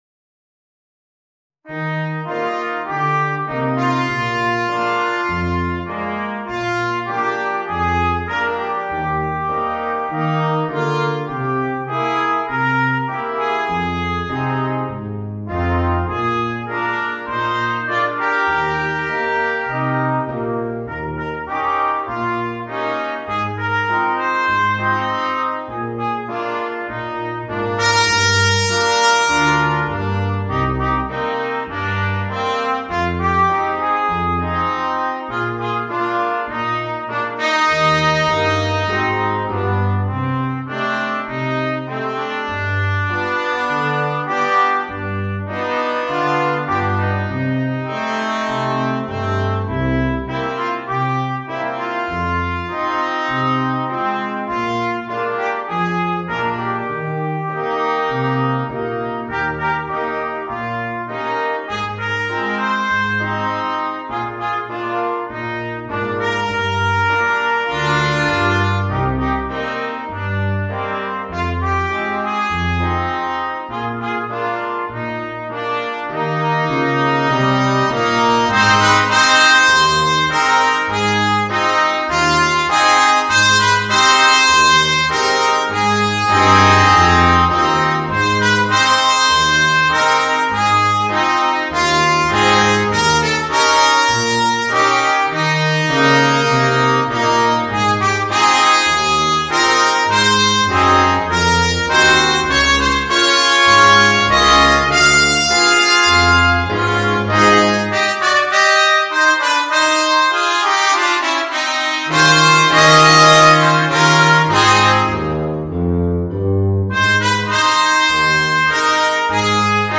Gattung: für variables Bläserquartett
Besetzung: Ensemble gemischt